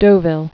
(dōvĭl, dō-vēl)